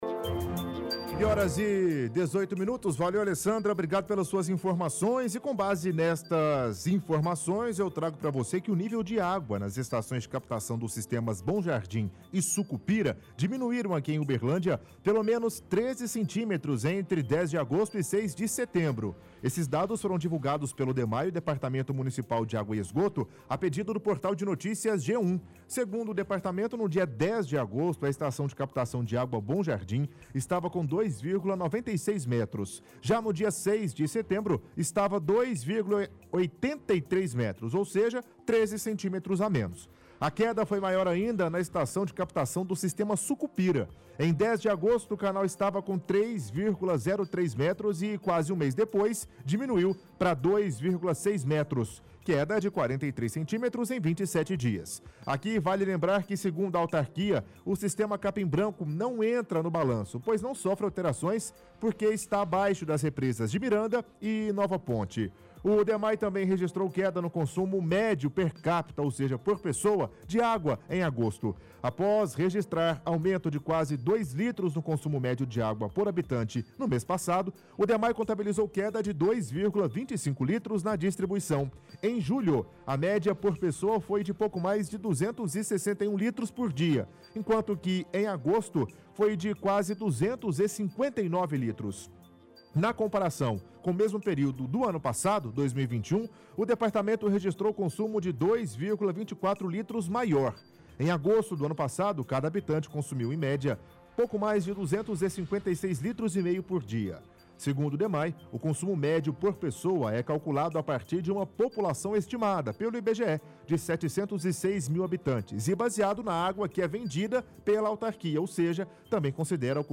– Leitura de reportagem do site do G1.